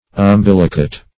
Umbilicate \Um*bil"i*cate\, Umbilicated \Um*bil"i*ca`ted\, a.